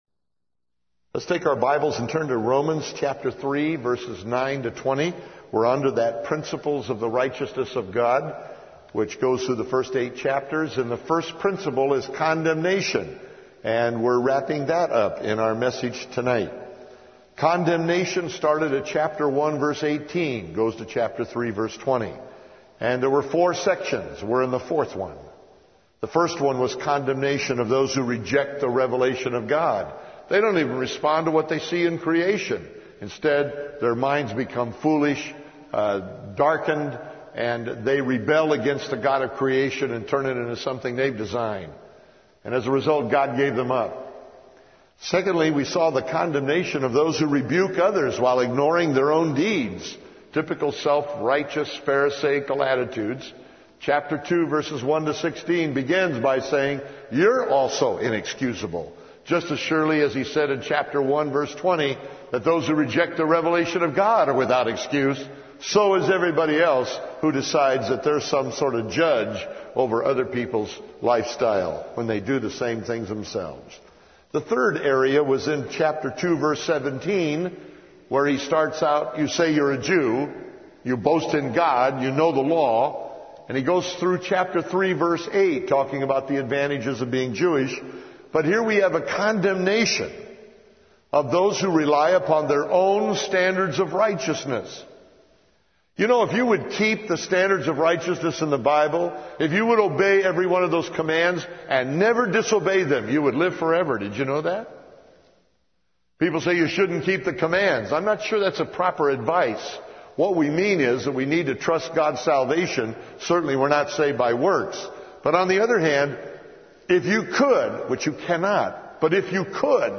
Audio Bible Studies